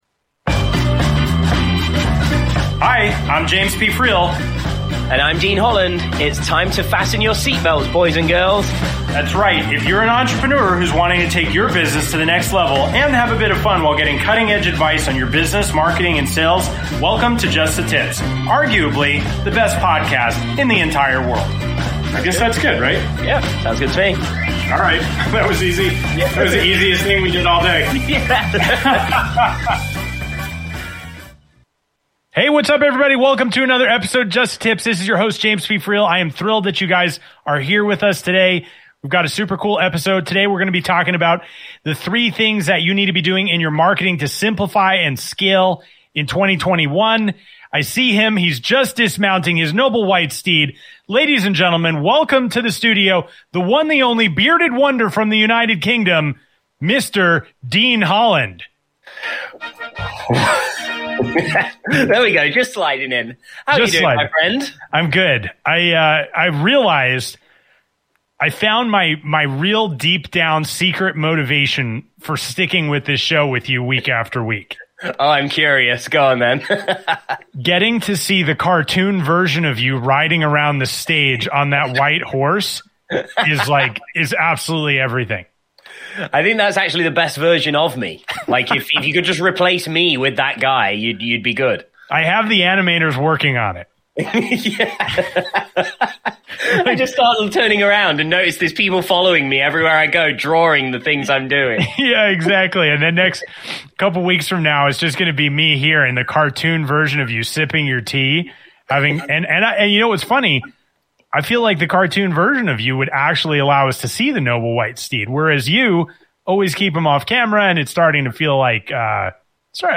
Talk Show Episode
Just The Tips is a business talk show for entrepreneurs and business owners who are tired of listening to the same old stodgy content. Interviewing (and sometimes roasting) top entrepreneurs from around the world, each episode is fun, witty and informative.